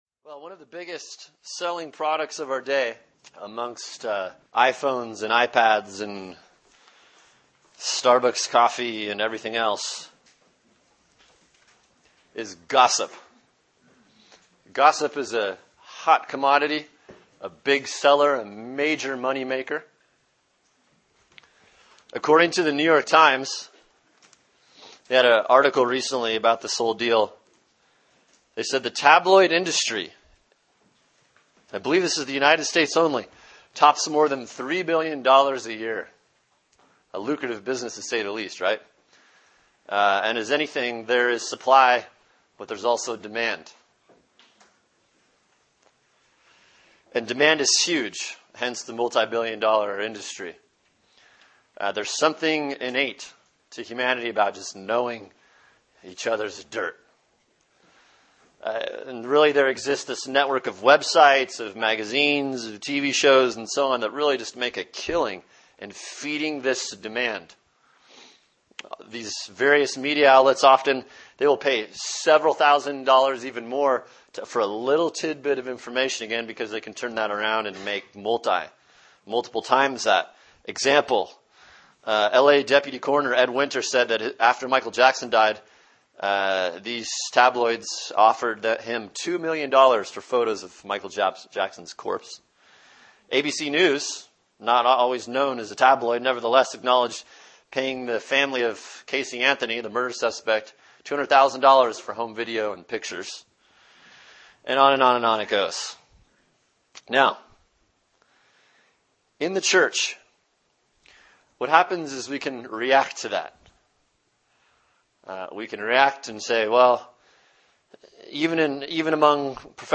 Sermon: Galatians 6:1-5 “Restoring One Another” Part 1 | Cornerstone Church - Jackson Hole